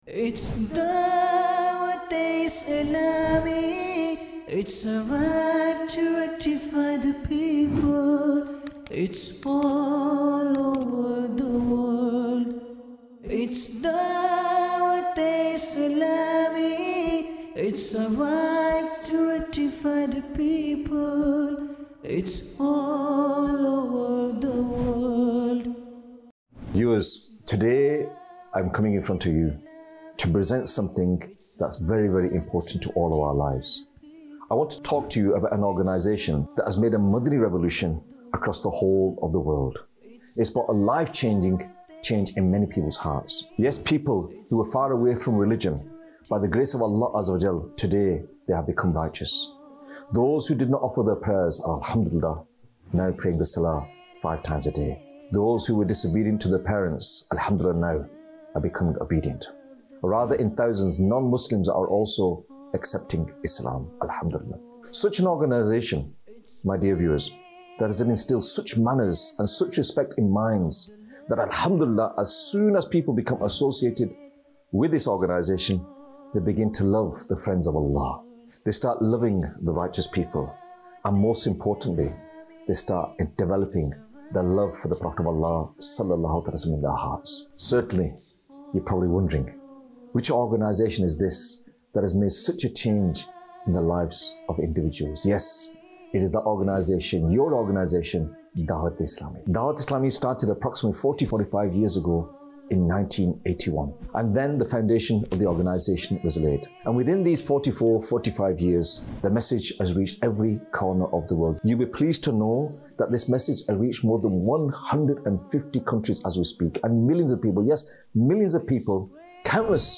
Introduction of Dawateislami | 19 Minutes Documentary 2026